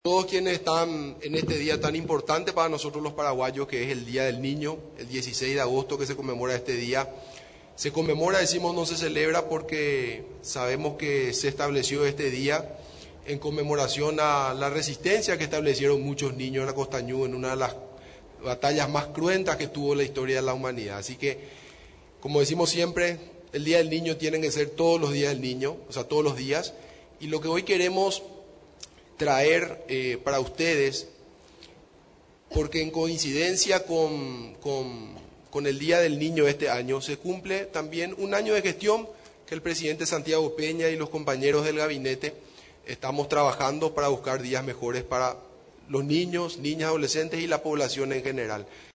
En el informe de Gestión, realizado en la Residencia Presidencial de Mburivichá Róga, estuvo presente en representación del Ejecutivo, la Primera Dama de la Nación, Leticia Ocampos, además de otras autoridades nacionales.